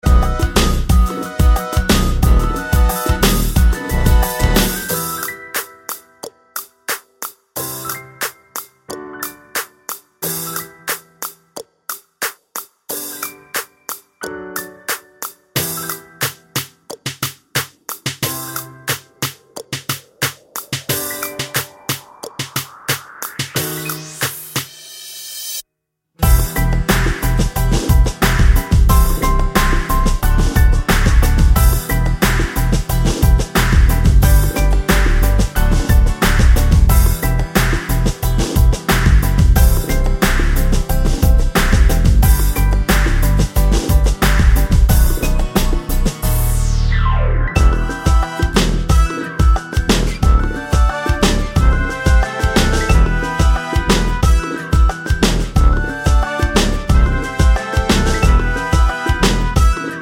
no Backing Vocals Soundtracks 3:22 Buy £1.50